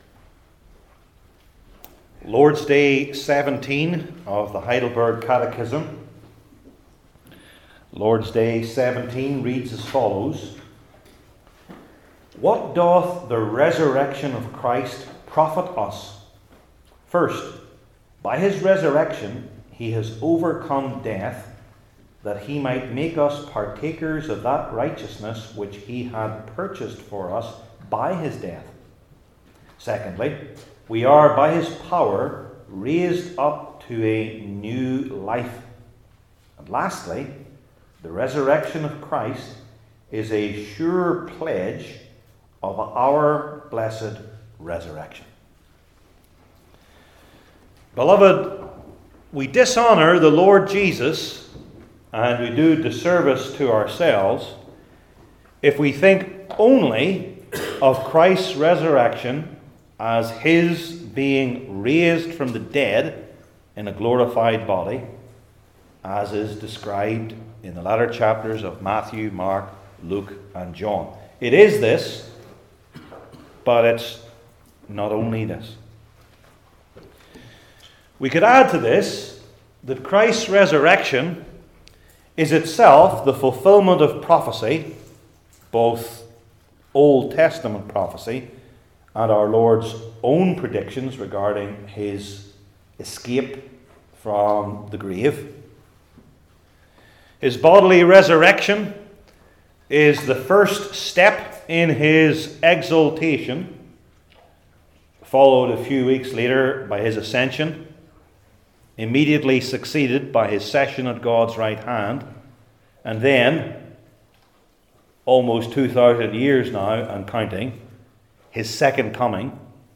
Romans 8:1-25 Service Type: Heidelberg Catechism Sermons I. Imputed Righteousness!